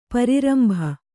♪ pari rambha